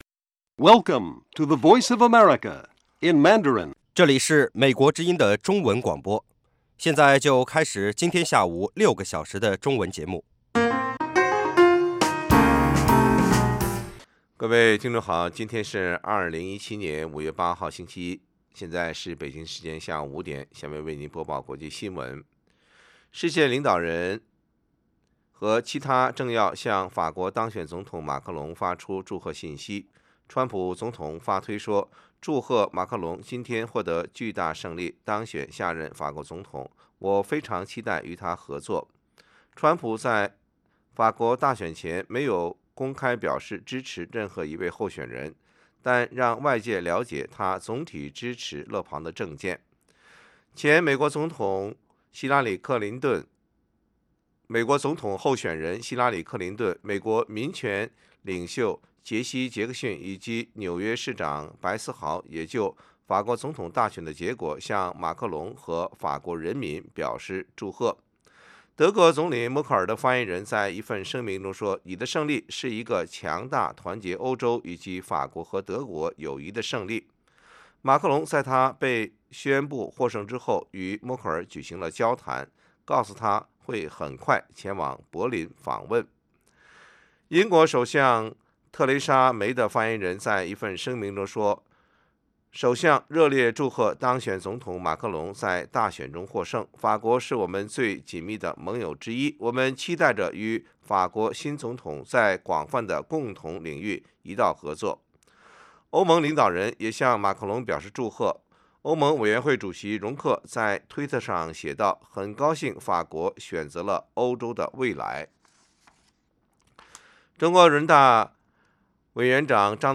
北京时间下午5-6点广播节目。广播内容包括国际新闻，新动态英语，以及《时事大家谈》(重播)